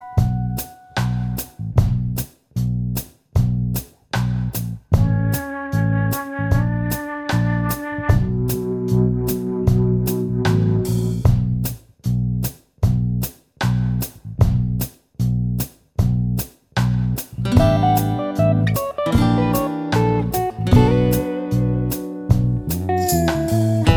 Minus Lead Guitar Pop (1990s) 3:25 Buy £1.50